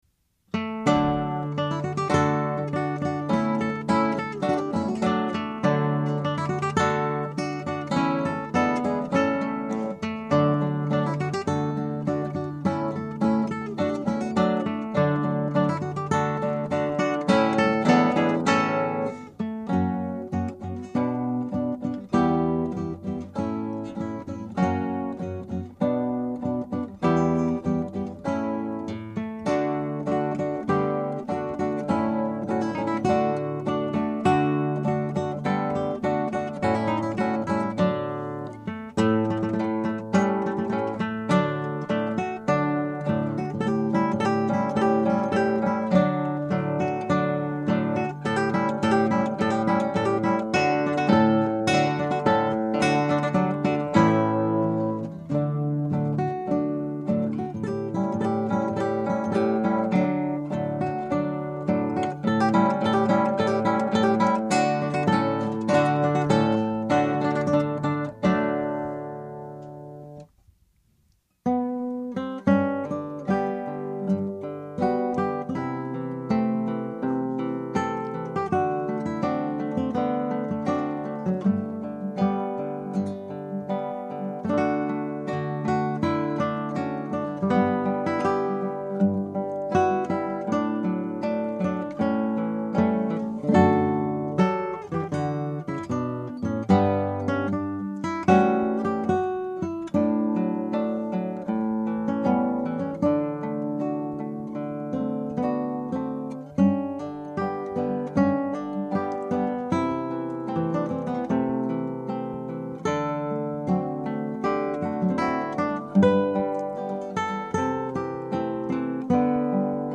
Scraps from the Operas arranged for Two Guitars
Scrap 1: Allegretto.
Scrap 2 (1:11): Andantino Cantabile.
Scrap 3 (2:24): Tempo di Valse.